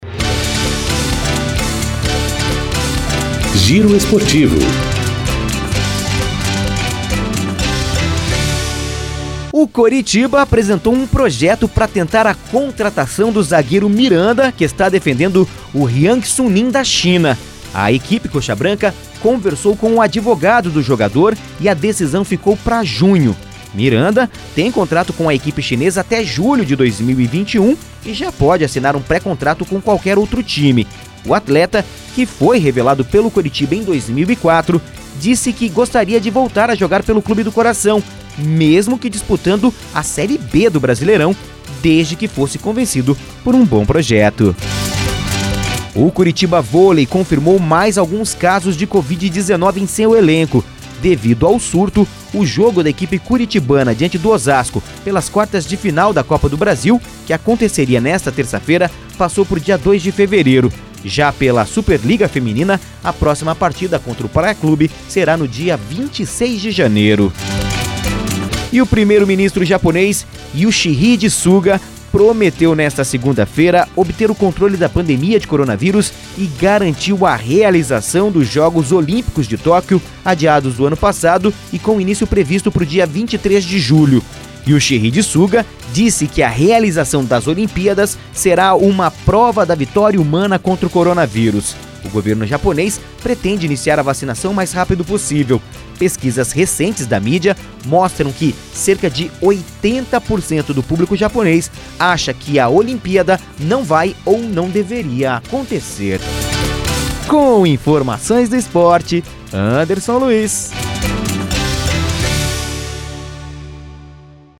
Giro Esportivo (COM TRILHA)